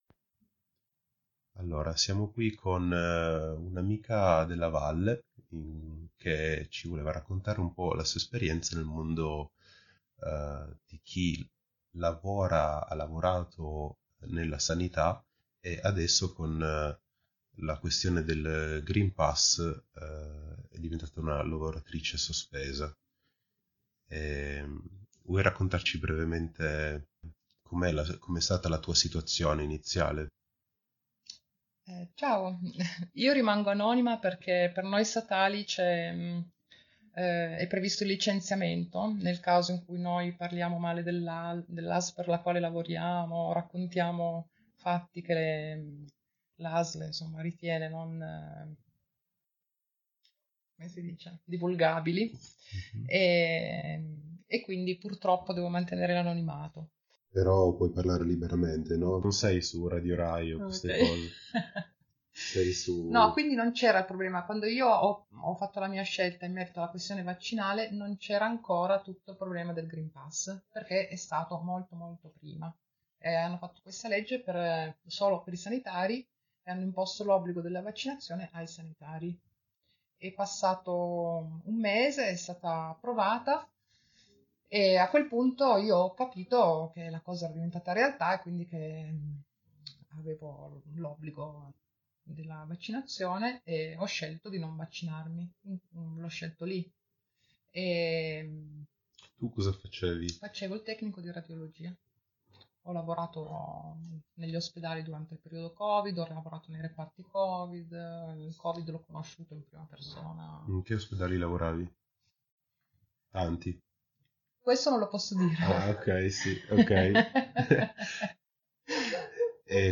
sfasciapassare-6-intervista2-sanitariasospesa.mp3